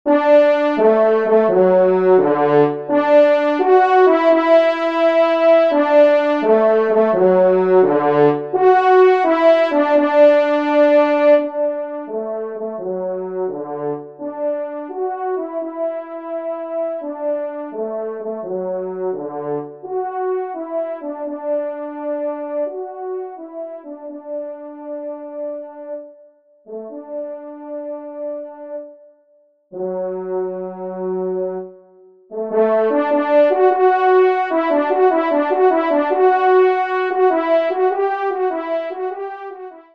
1e Trompe